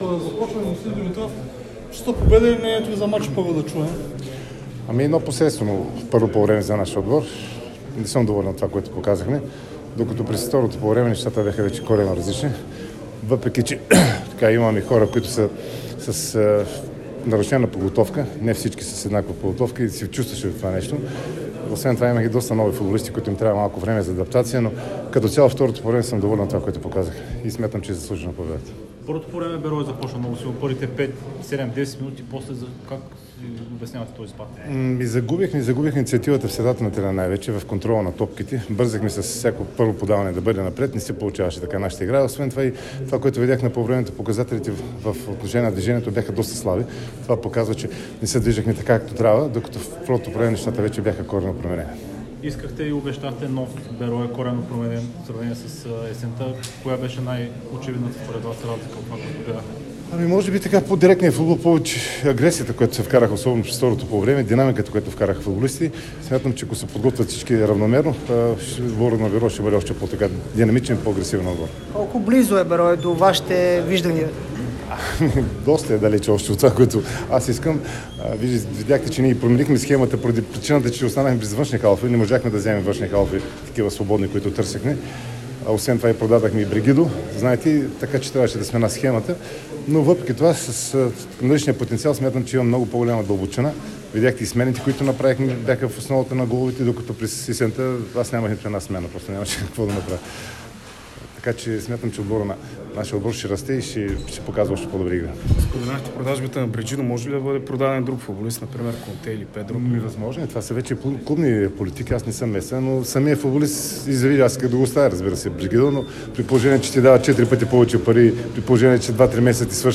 Треньорът на Беро Димитът Димитров – Херо говори след победата на старозагорци с 2:0 срещу Етър у дома. Наставникът остана доволен от играта на своите през втората част, заяви, че не се очакват нови попълнения до лятото и загатна за промени в тактическата постройка: